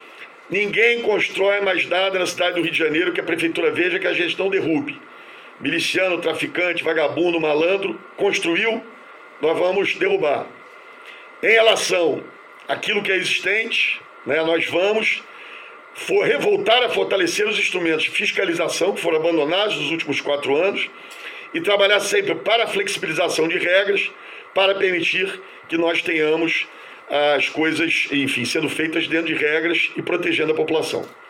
Eduardo Paes fez o comentário durante a divulgação do Boletim epidemiológico, nesta sexta-feira, no Centro de Operações Rio.